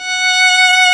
Index of /90_sSampleCDs/Roland - String Master Series/STR_Violin 2&3vb/STR_Vln3 % marc
STR VLN3 F#4.wav